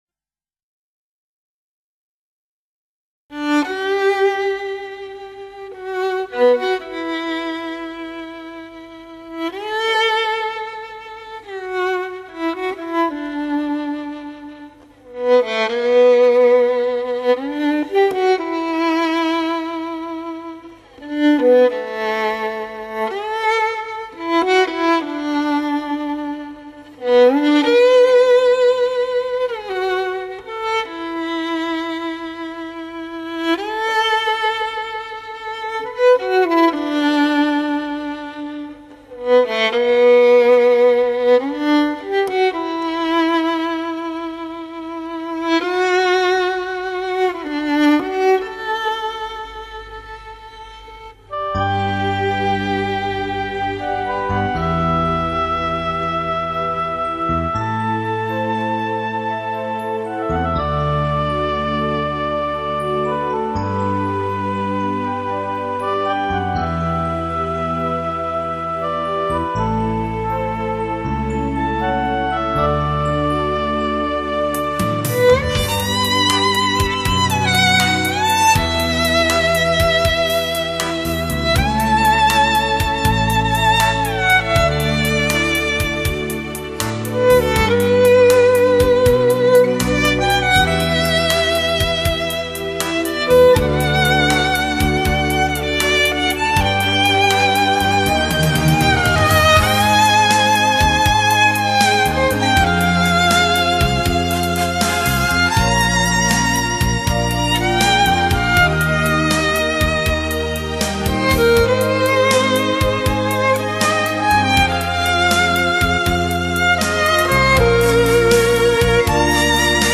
小提琴